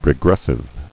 [re·gres·sive]